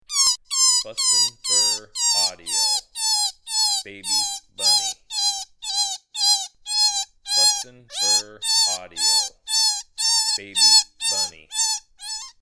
BFA Baby Bunny: Baby Eastern Cottontail rabbit in distress.
BFA Baby Bunny Sample.mp3